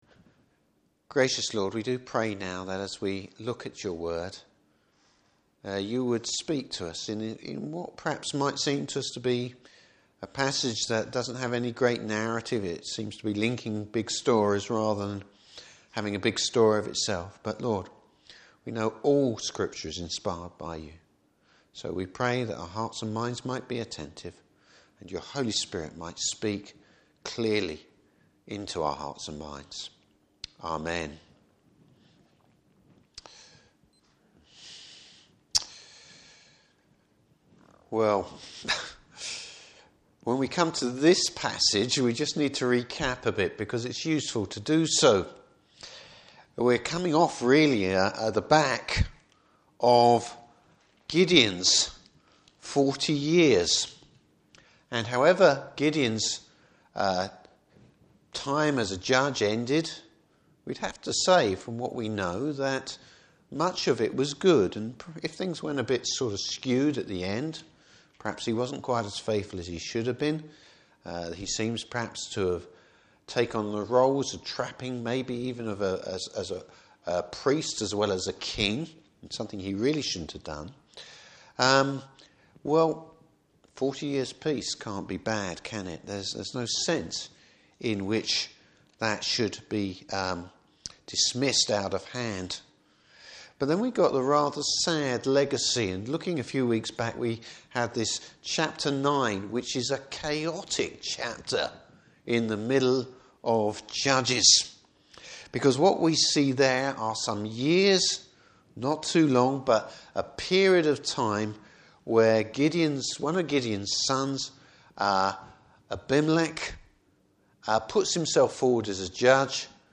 Service Type: Evening Service Bible Text: Judges 10:1-18.